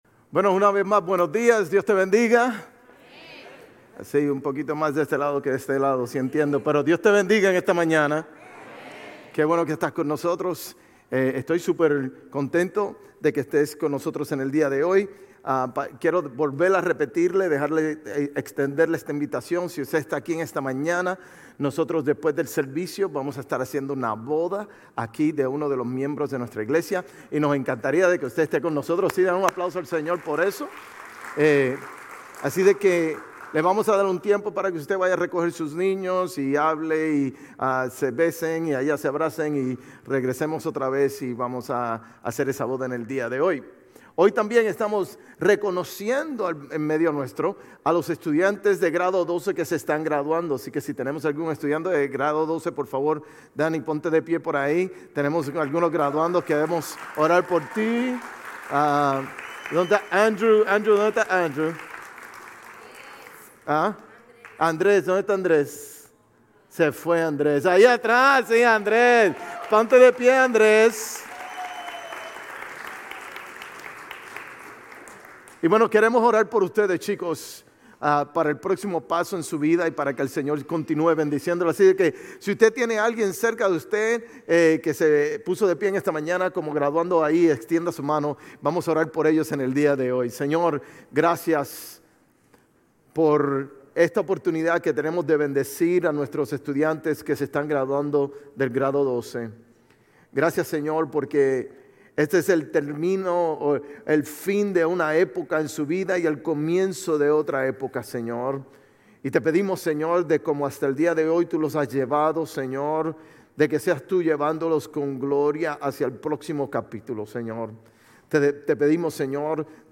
Sermones Grace Español 5_4 Grace Espanol Campus May 05 2025 | 00:45:08 1x 00:00 / 00:45:08 Subscribe Share RSS Feed Share Link Embed